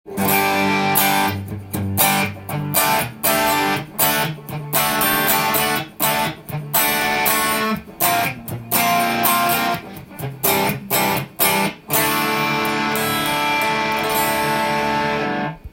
リアのハムバッカーで歪ませて弾いてみましたが、
箱鳴りが凄いですね！
生音がジャキジャキしていたので、それがマイクにのって
シンライン独特のミドルレンジで　普通のソリッドギターでは出せない